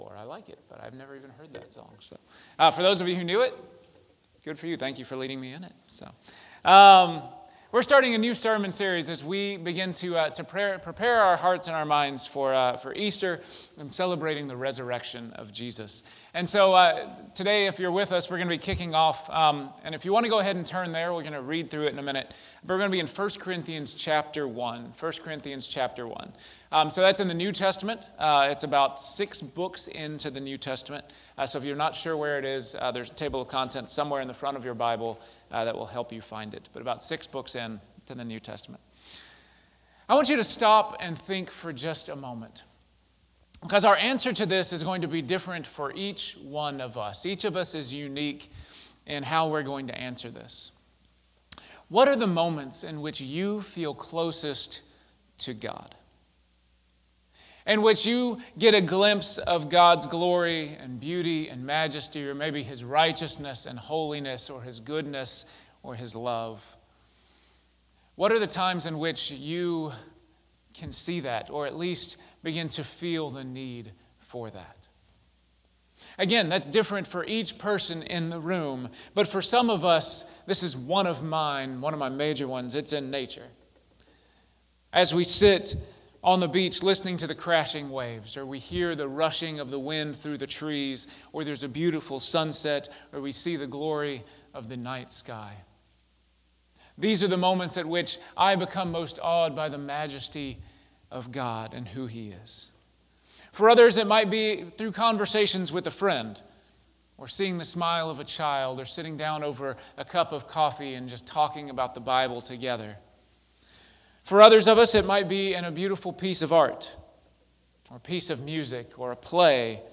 Weekly Sermon Audio